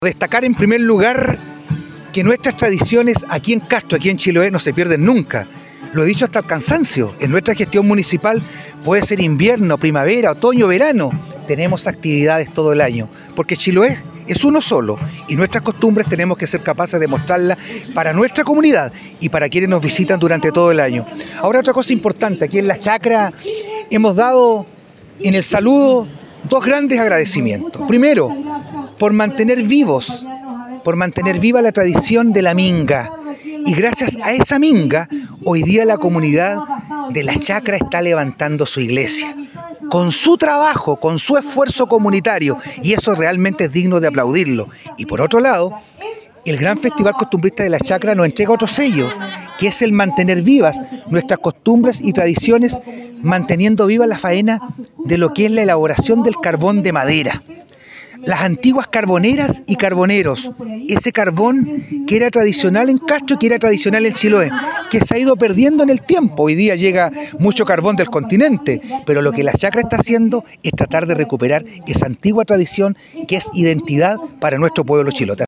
En la oportunidad el alcalde de Castro, Juan Eduardo Vera, indicó:
ALCALDE-VERA-LA-CHACRA.mp3